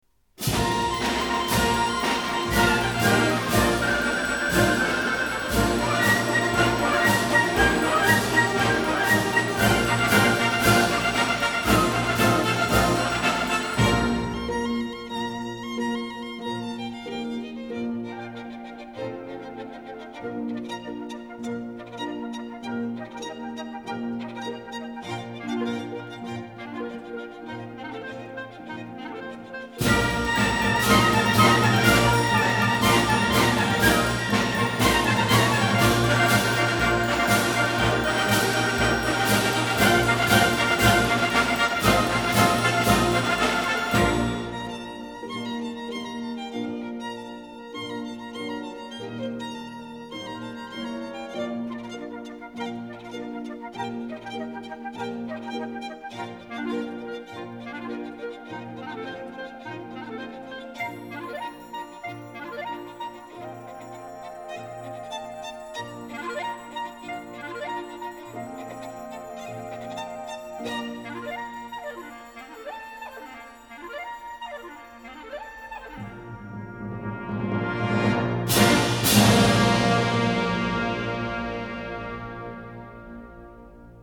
Et à la reprise de l’Alborada, même punition à la lettre H !